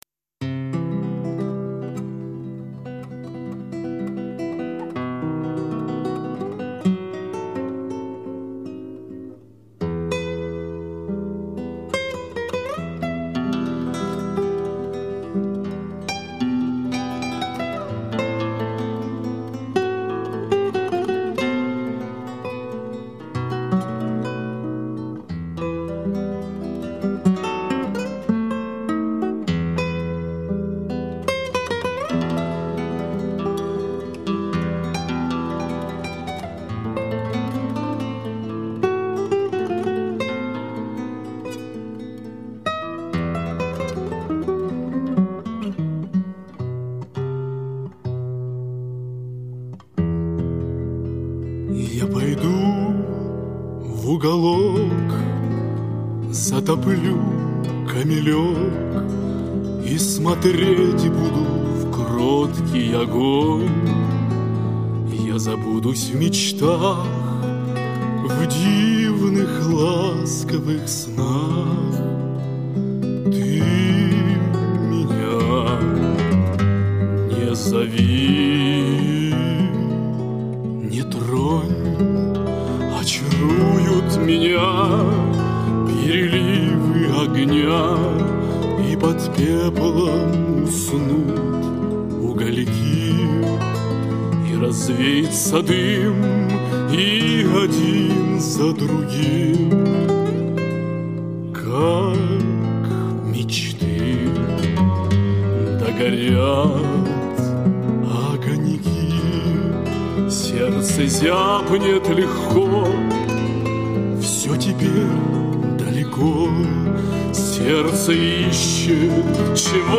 Вы услышите старинные русские романсы, а также романсы, написанные самим исполнителем на стихи поэтов золотого и серебряного века.
гитара, вокал